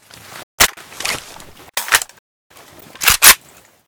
46265b6fcc Divergent / mods / Boomsticks and Sharpsticks / gamedata / sounds / weapons / ak12custom / reload.ogg 44 KiB (Stored with Git LFS) Raw History
reload.ogg